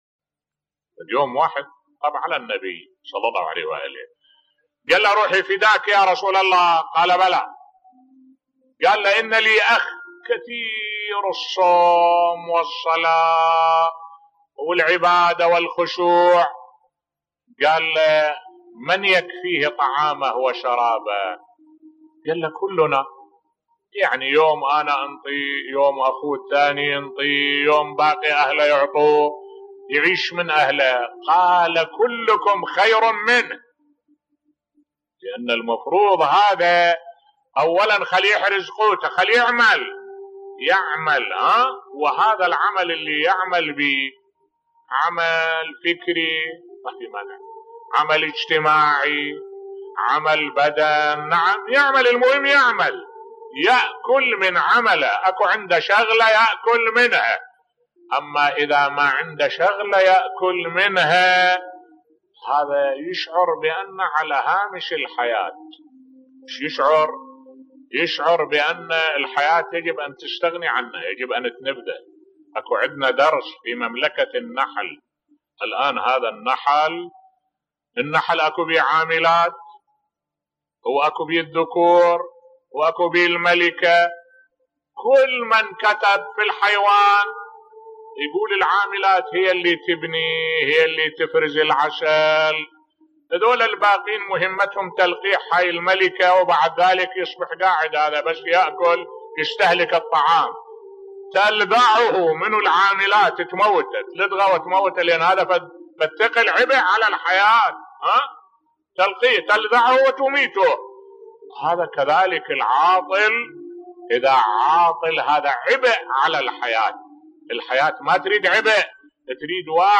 ملف صوتی قصة و عبرة كلكم خير منهُ بصوت الشيخ الدكتور أحمد الوائلي